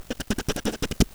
knife1.wav